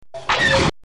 Star Wars Laser Sound Effect Free Download
Star Wars Laser